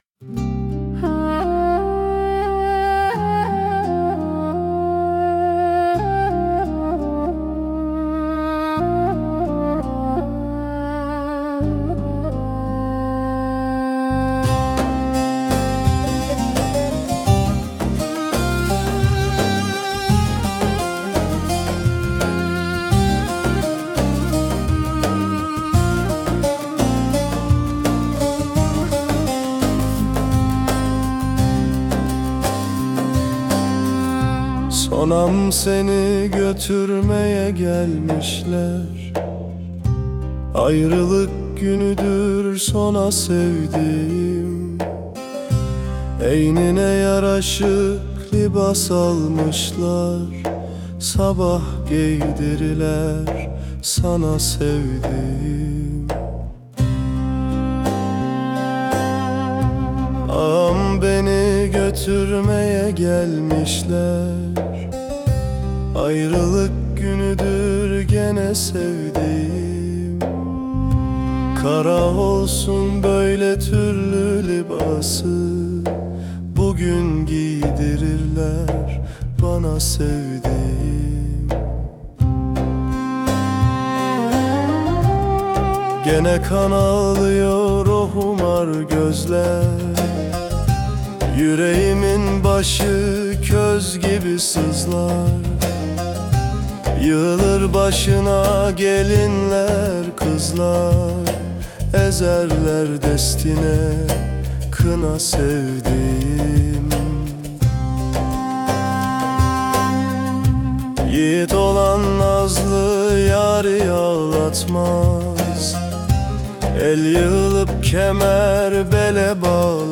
🎤 Vokalli 19.10.2025